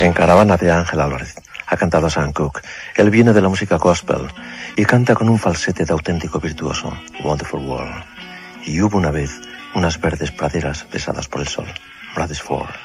Presentació d'un tema musical.
Musical